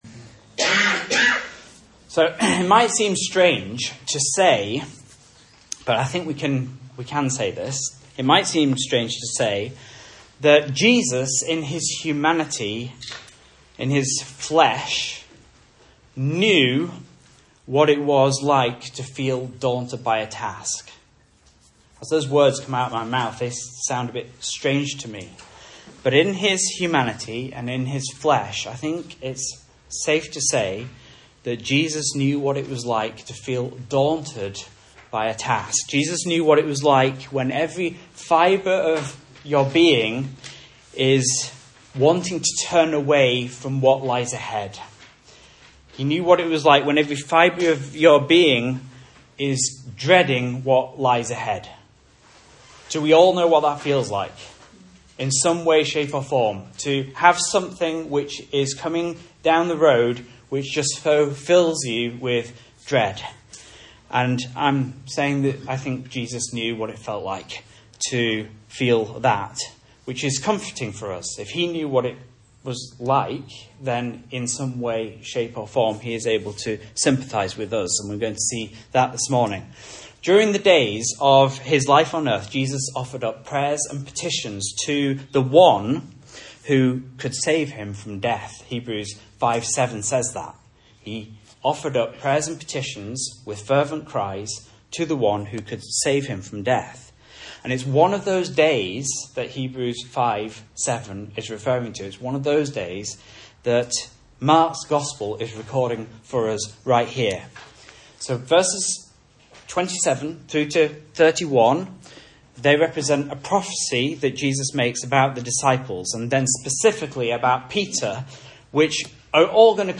Message Scripture: Mark 14:27-51 | Listen